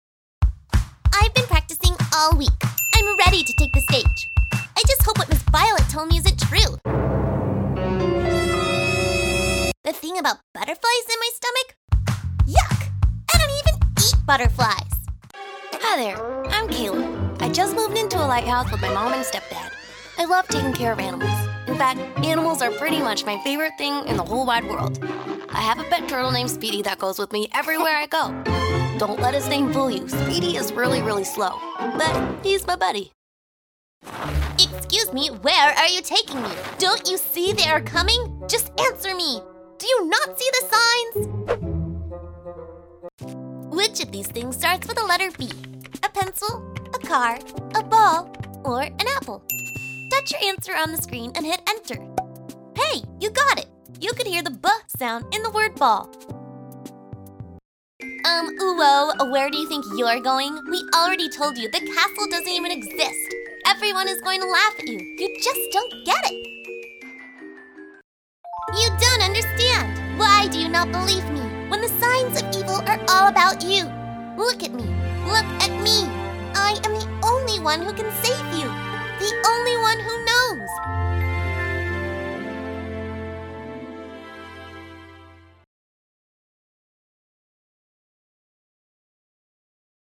Animation Demo